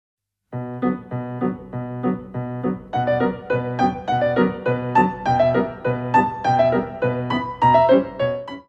Warm-up jump